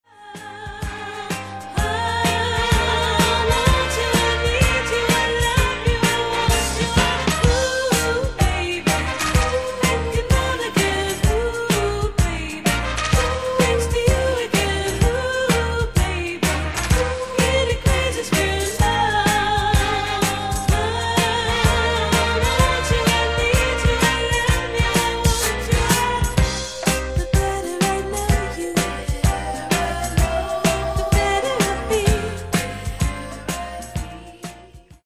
Genere:   Disco Soul